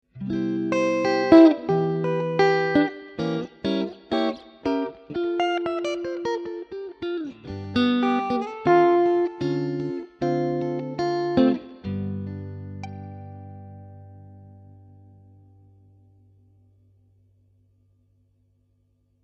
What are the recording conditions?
The performance of this circuit is very good (at least to my ears).